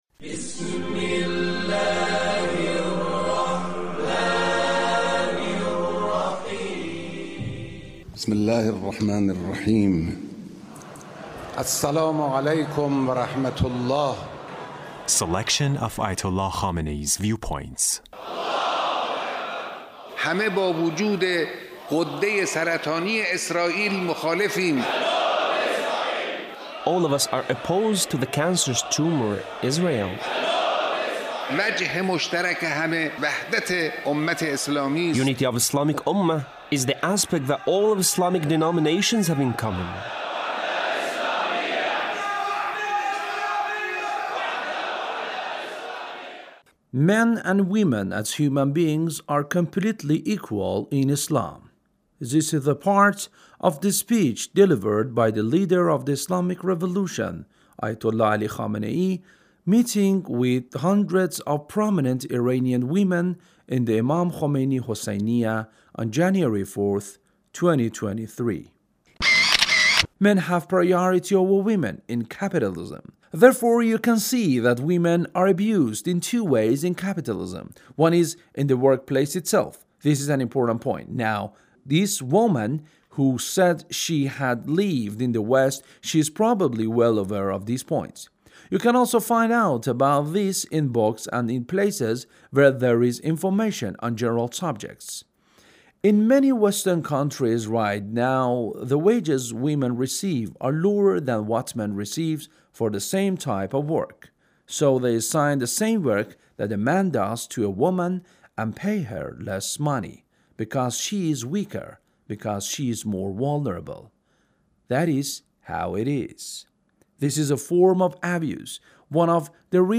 Leader's Speech meeting with hundreds of prominent Iranian women